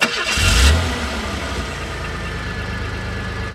start_out.ogg